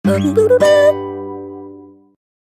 Humming.ogg